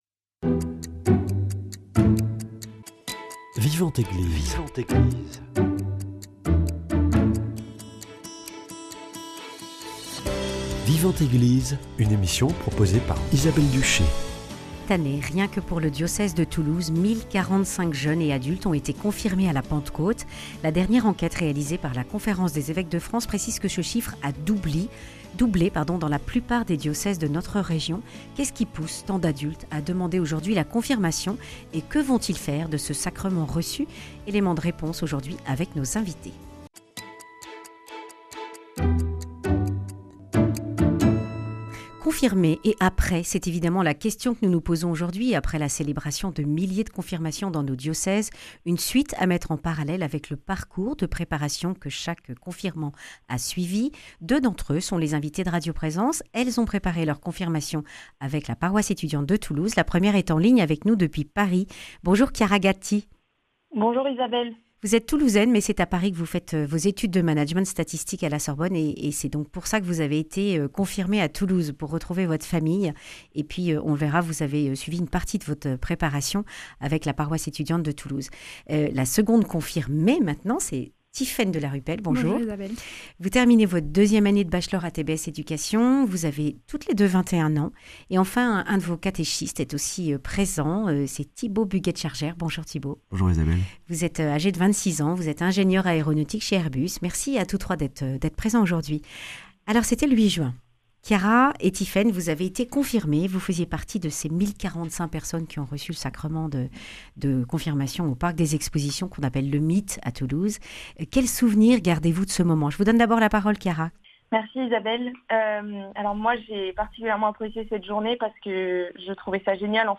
1.045 jeunes et adultes ont été confirmés dimanche de Pentecôte à Toulouse. Deux d’entre eux font part de leur cheminement.